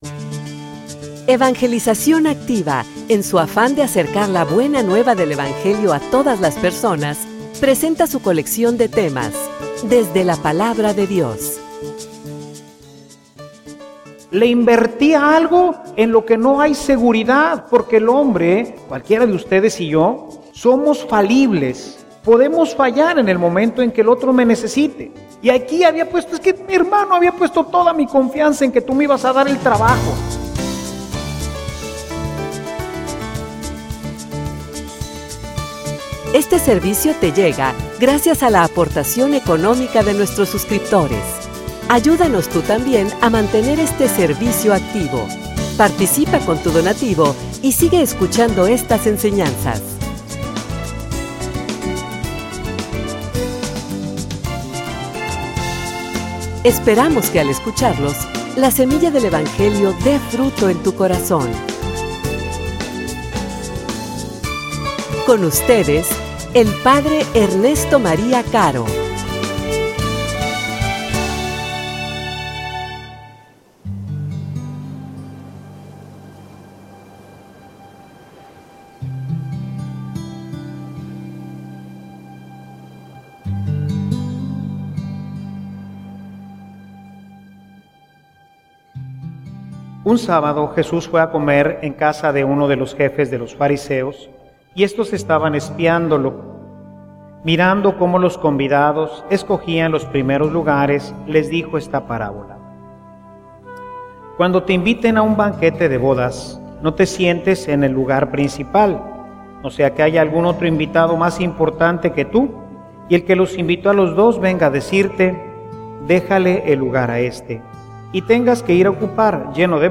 homilia_Invierte_adecuadamente.mp3